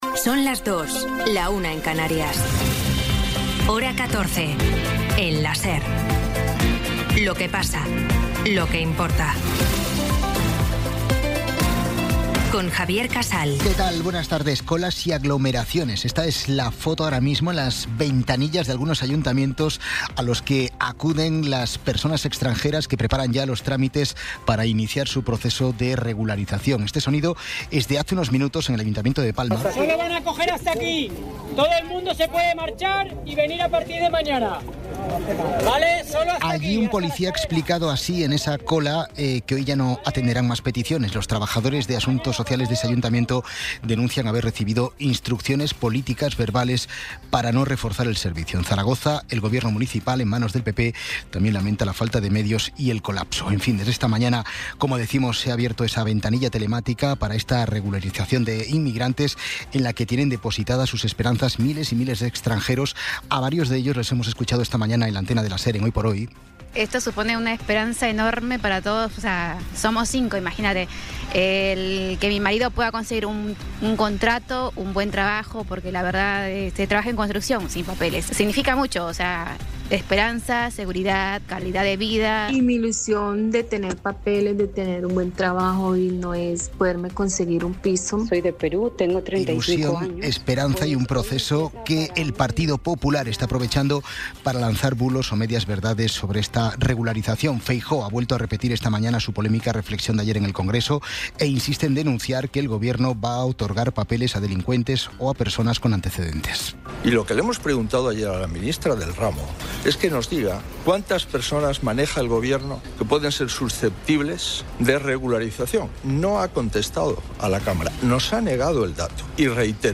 Resumen informativo con las noticias más destacadas del 16 de abril de 2026 a las dos de la tarde.